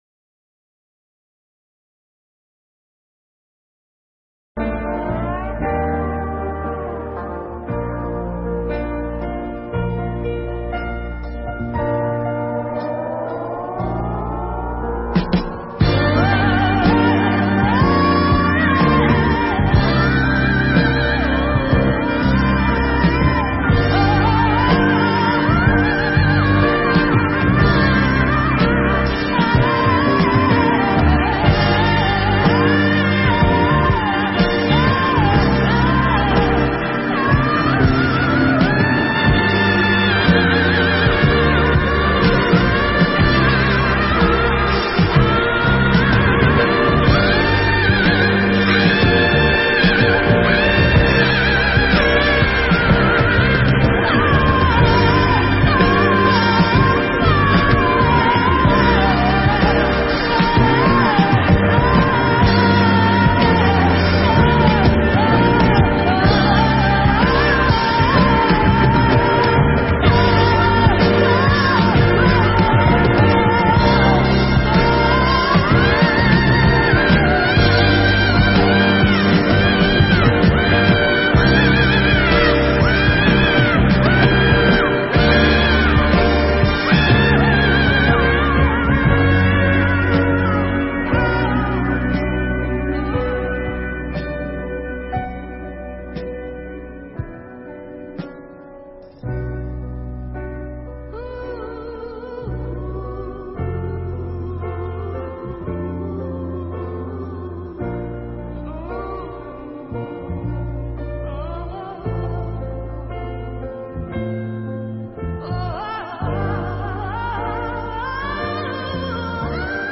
Mp3 Thuyết Pháp Động đất sóng thần hiểm họa khôn lường